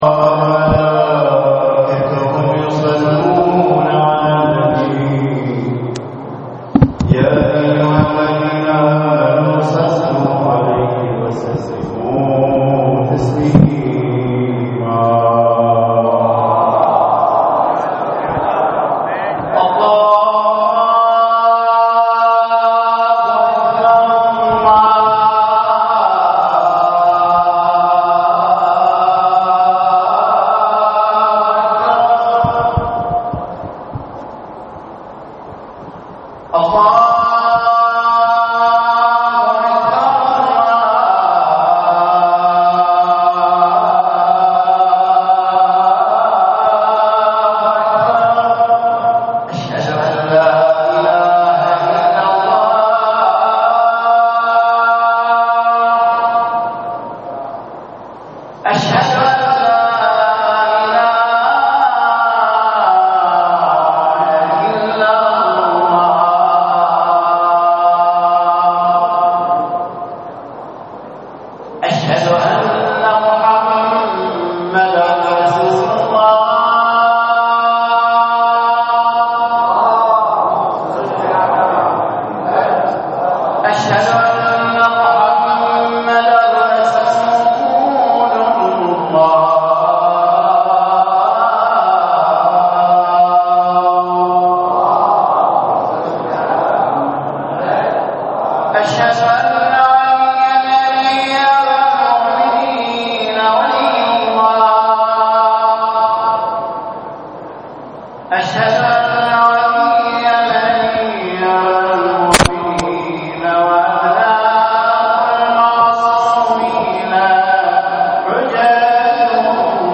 للاستماع الى خطبة الجمعة الرجاء اضغط هنا